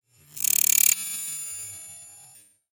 Звуки киберпанка
Голограмма продемонстрировала рекламу со звуком